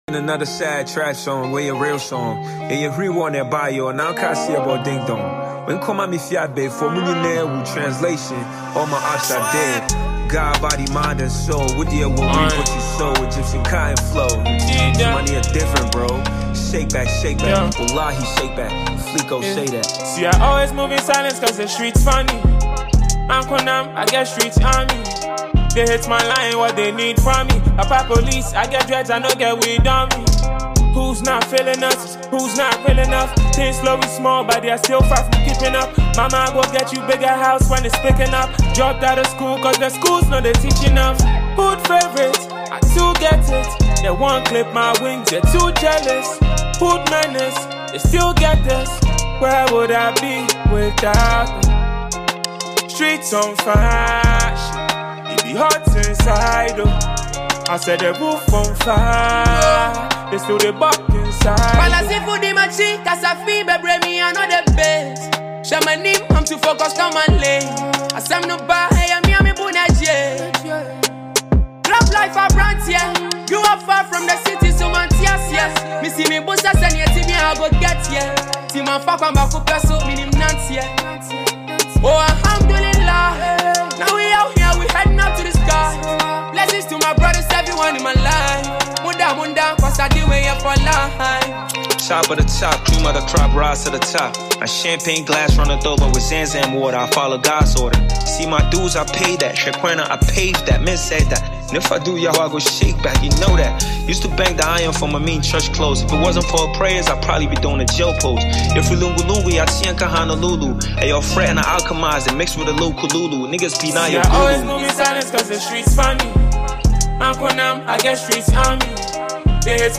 Ghanaian award-winning rapper
heavy joint